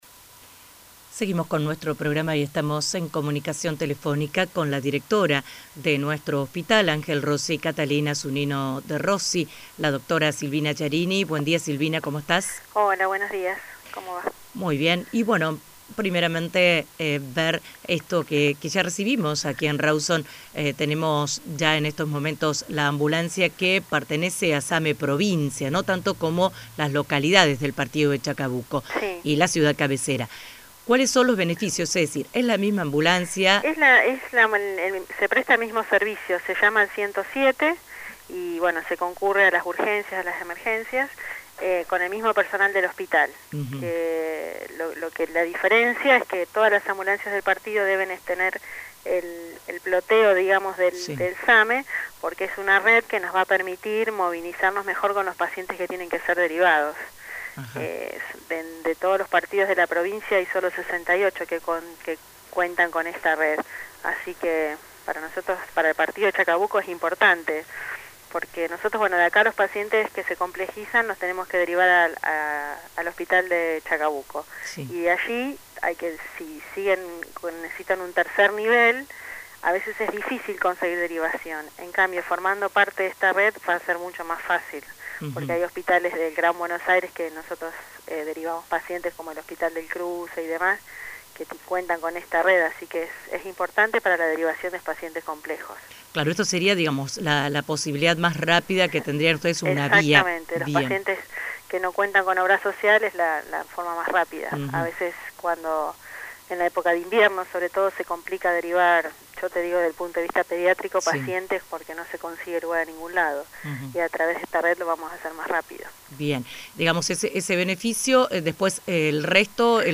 La noticia se completa con el audio de la entrevista